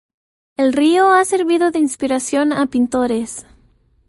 ser‧vi‧do
/seɾˈbido/